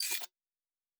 pgs/Assets/Audio/Sci-Fi Sounds/Weapons/Additional Weapon Sounds 3_1.wav at master
Additional Weapon Sounds 3_1.wav